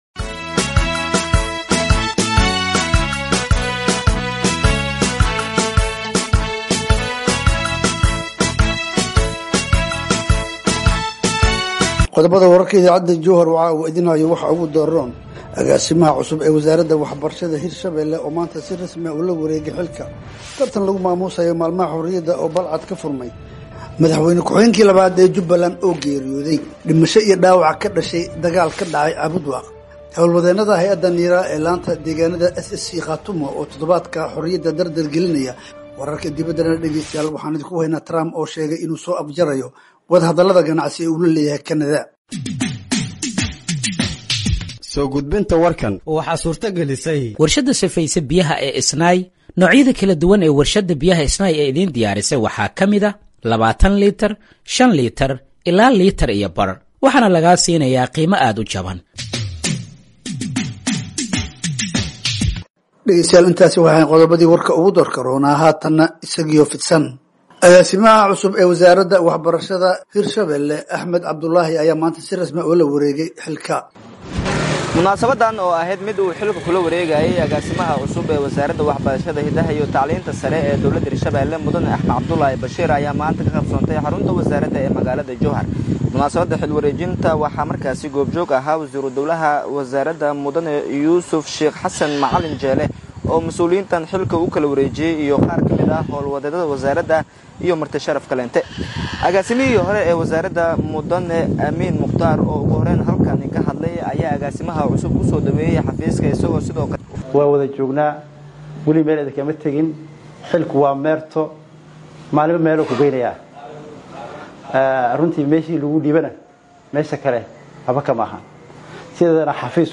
Halkaan Hoose ka Dhageeyso Warka Habeenimo ee Radiojowhar